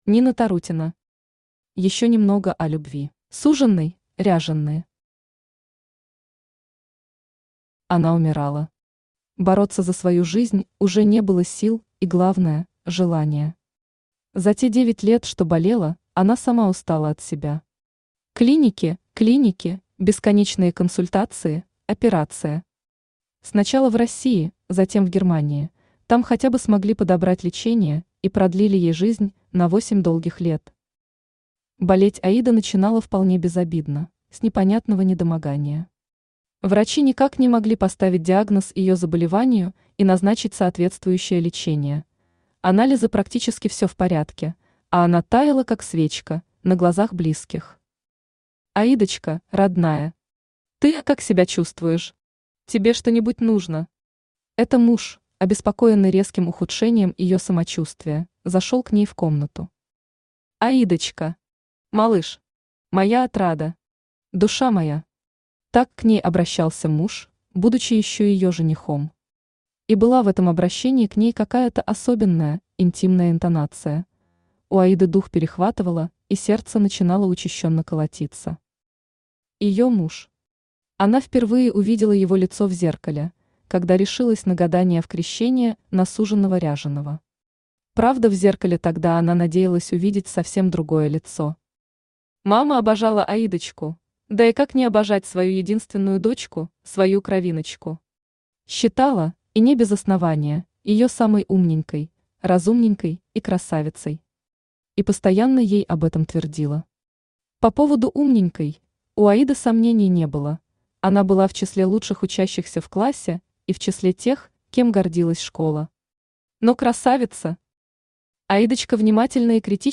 Автор Нина Тарутина Читает аудиокнигу Авточтец ЛитРес.